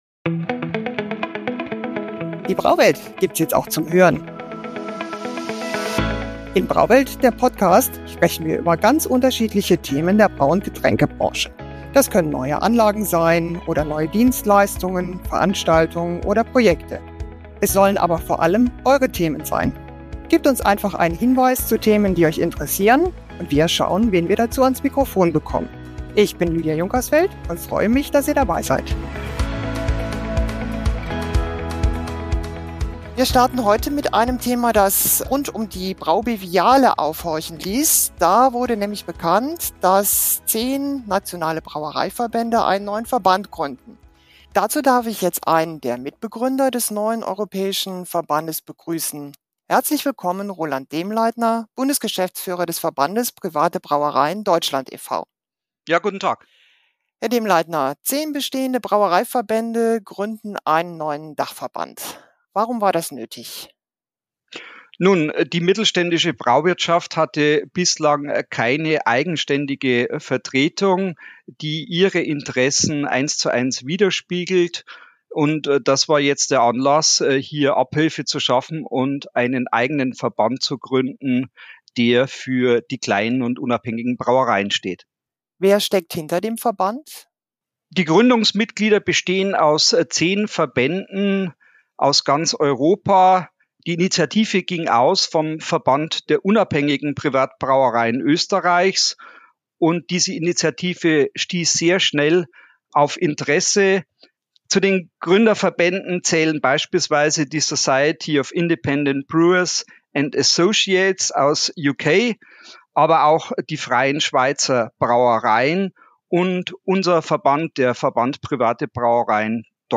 In der Premiere unseres neuen Formats „BRAUWELT – der Podcast“ geht es um den Ende 2024 neu gegründeten Verband Independent Brewers of Europe. Im Gespräch